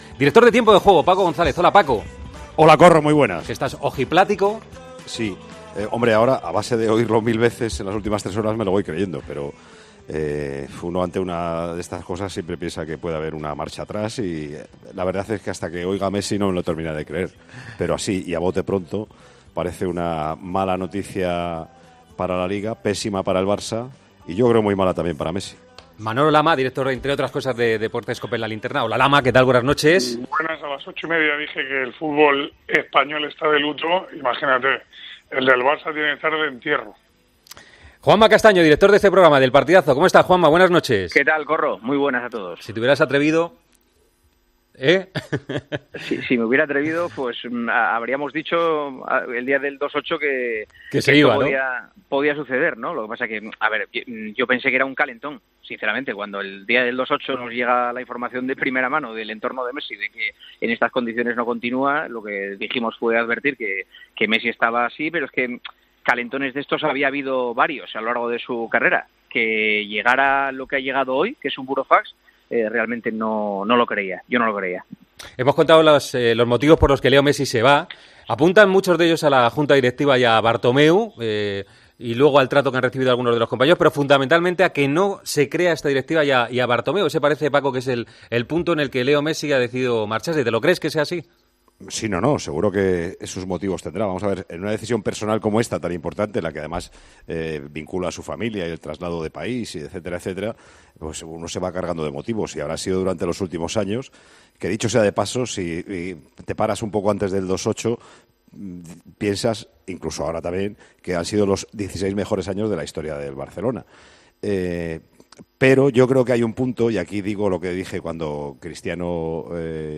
AUDIO: Paco González, Manolo Lama y Juanma Castaño hablan sobre la noticia de la petición de Messi de salir del Barcelona.